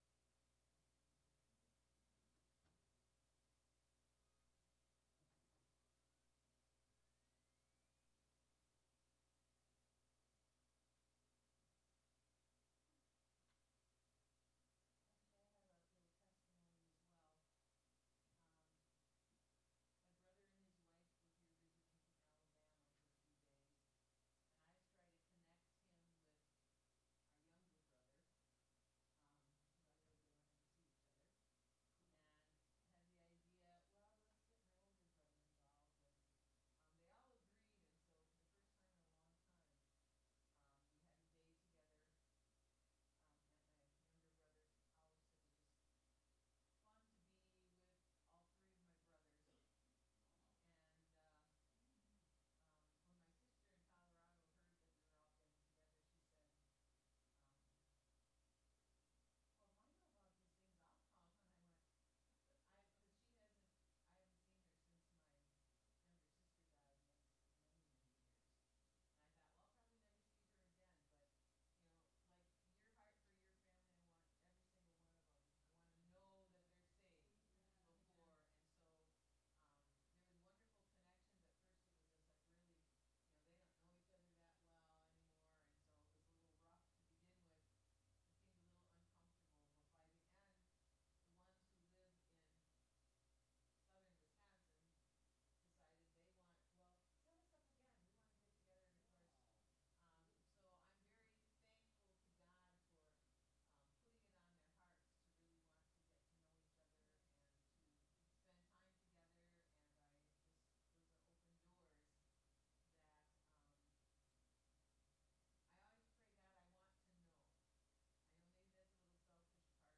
Sermons | The City of Hope